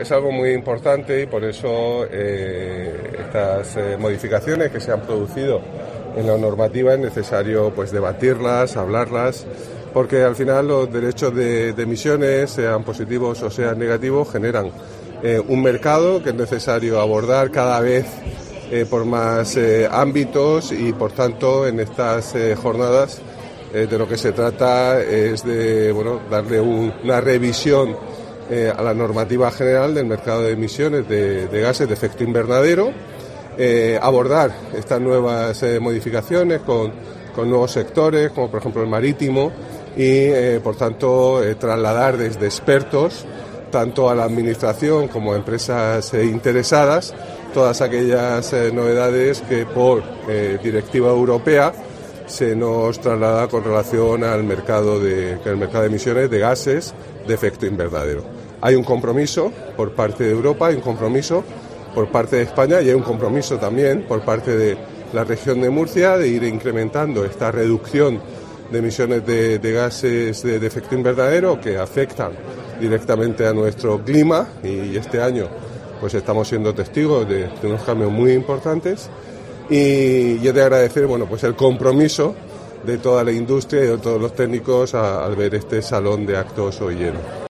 Así lo puso de manifiesto el consejero de Medio Ambiente, Universidades, Investigación y Mar Menor, Juan María Vázquez, durante la inauguración de la Jornada ‘Novedades en la normativa del sistema europeo de comercio de derechos de emisión (EU ETS)’.